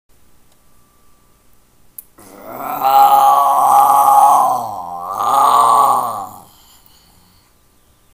ZOMBIE
ZOMBIE - Tono movil - EFECTOS DE SONIDO
Tonos gratis para tu telefono – NUEVOS EFECTOS DE SONIDO DE AMBIENTE de ZOMBIE
zombie.mp3